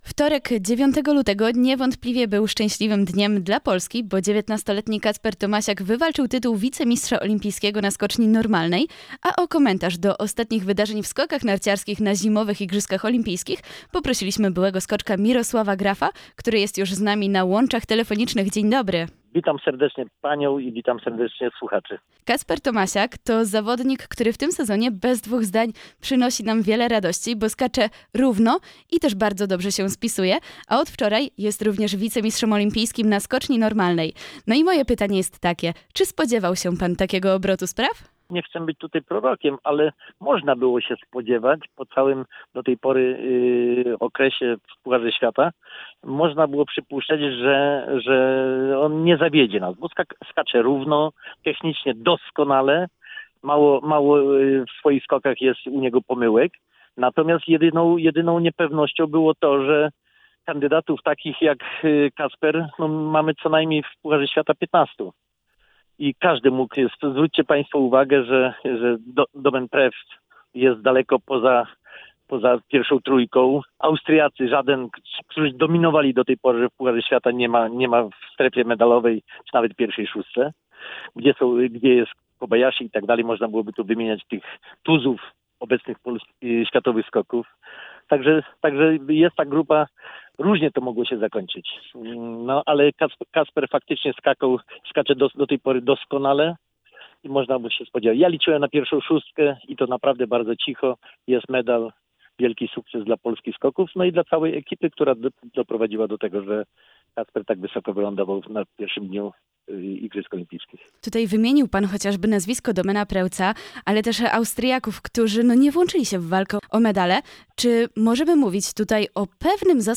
Komentarz-do-skokow.mp3